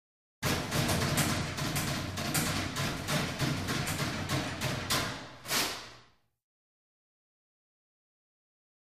Paper Towel | Sneak On The Lot
Locker Room; Paper Being Dispensed From Dispenser.